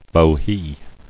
[bo·hea]